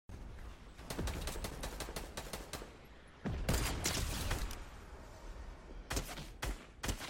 OG Fortnite Headshot Sniped, sound effects free download